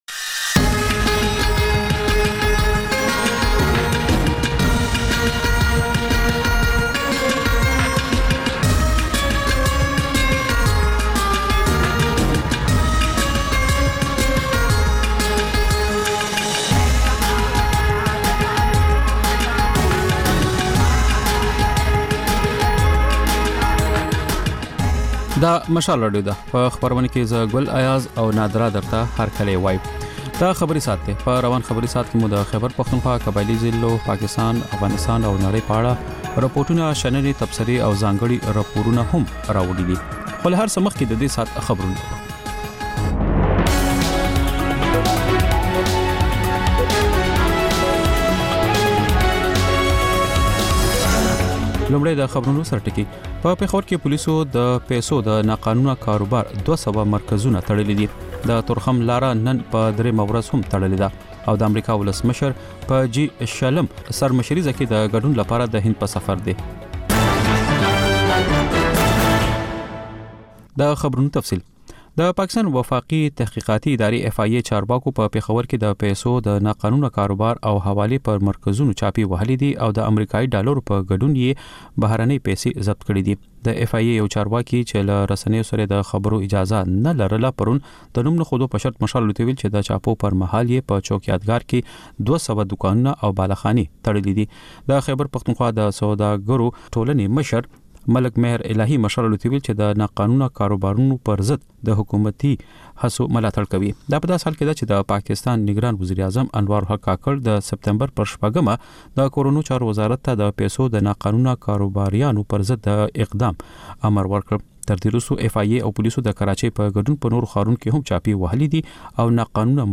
دلته د مشال راډیو د ۱۴ ساعته خپرونو دویمه او وروستۍ خبري ګړۍ تکرار اورئ. په دې خپرونه کې تر خبرونو وروسته بېلا بېل سیمه ییز او نړیوال رپورټونه، شننې، مرکې، رسنیو ته کتنې، کلتوري او ټولنیز رپورټونه خپرېږي.